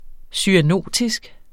Udtale [ syaˈnoˀtisg ]